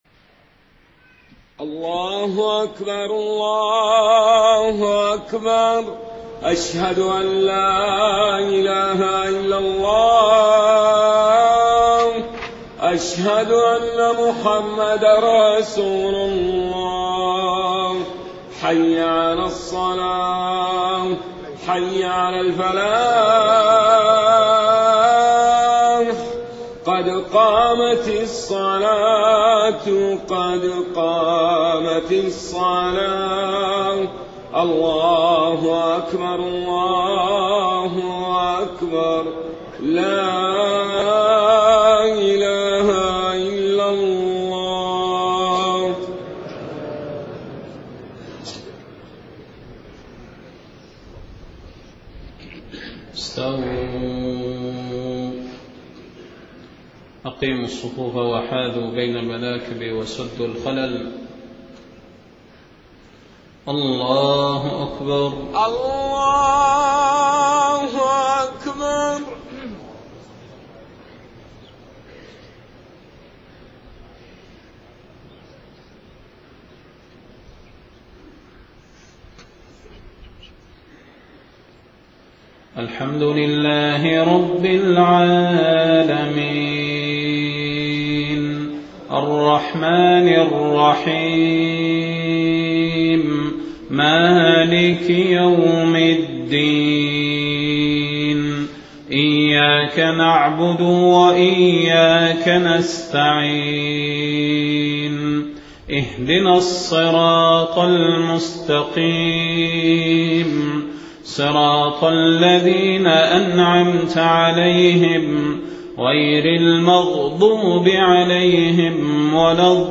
صلاة المغرب 27 محرم 1430هـ من سورة الشورى 36-46 > 1430 🕌 > الفروض - تلاوات الحرمين